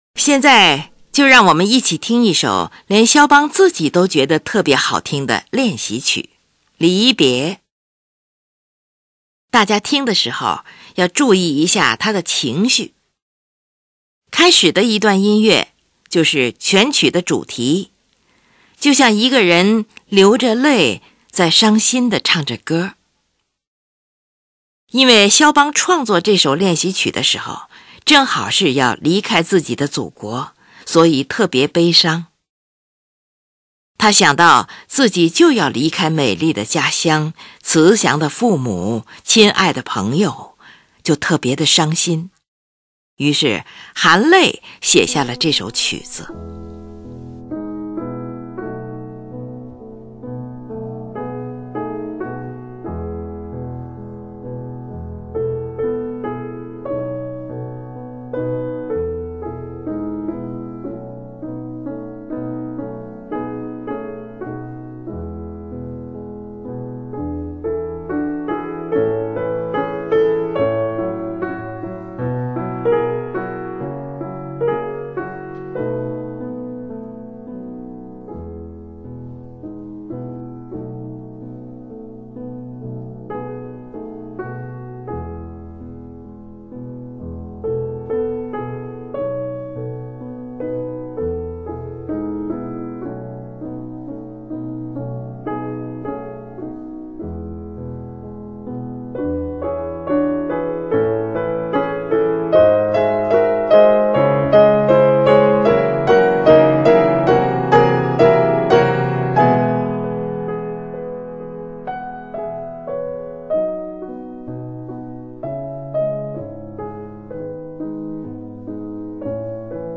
开始的一段音乐就是全曲的主题，就像一个人流着泪在伤心地唱着歌。
最后，前面出现过的如歌主题再次回来，情绪也由激动慢慢平缓。